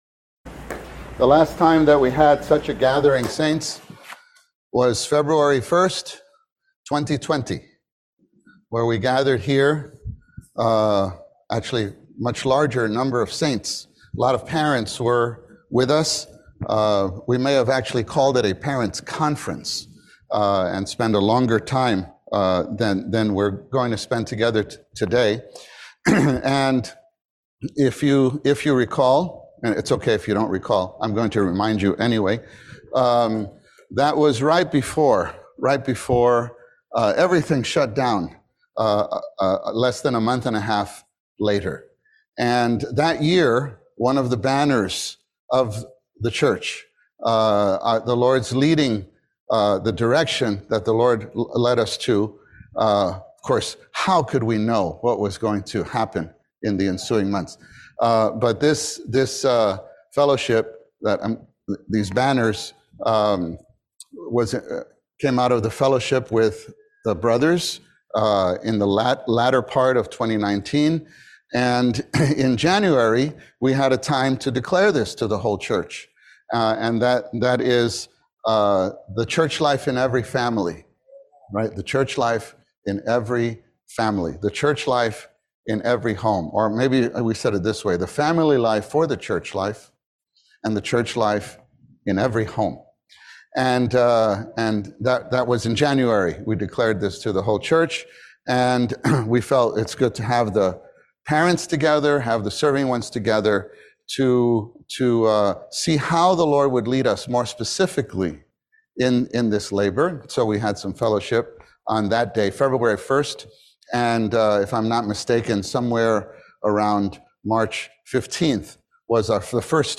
This conference was held at at KPCC (Camp Penuel)